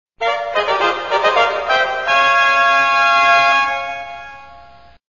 a flourish on a trumpet: a tucket sounded at a distance
Fanfare2
fanfare2.wav